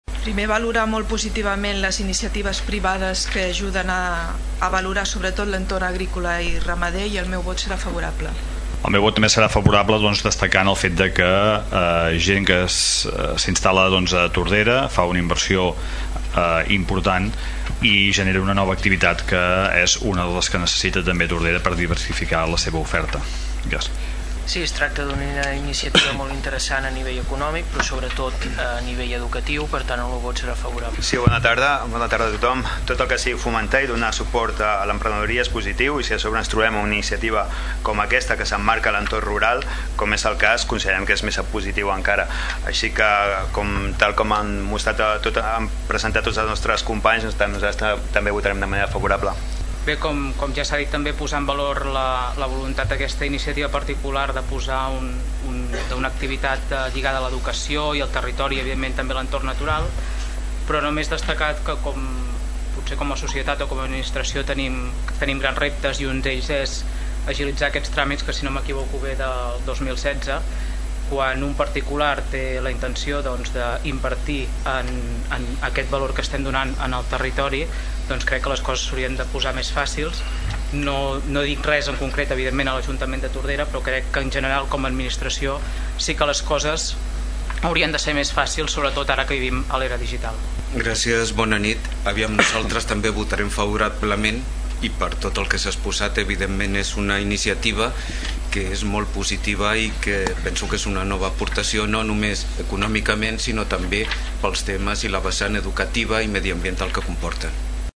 Al passat ple municipal es va aprovar el text refós del Pla especial per a la implantació d’una Aula d’entorn rural a la finca Mas Turon del veïnat de Sant Daniel.
L’oposició valorava favorablement les iniciatives particulars, oferint noves activitats educatives. Votaven favorablement els regidors no-adscrits Sílvia Mateos i Xavier Pla, Xavier Martin del PP, Salvador Giralt de Som Tordera, Anna Serra per part d’ERC i Rafa Delgado per part de PSC.